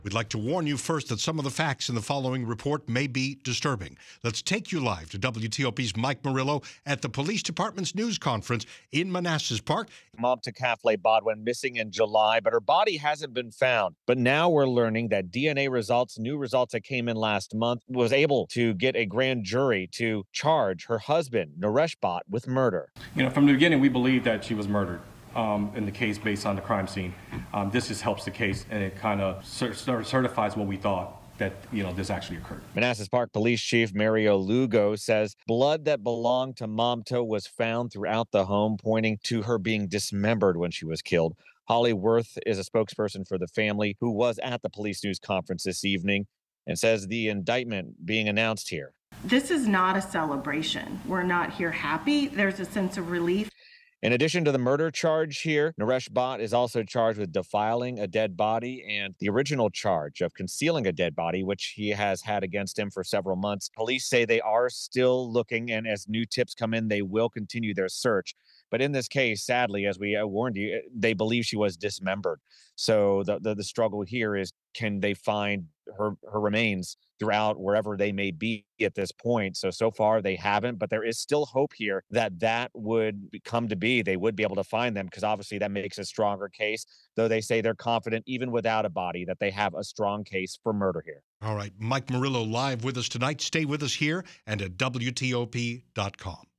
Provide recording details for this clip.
at Monday evening's news conference in Prince William County.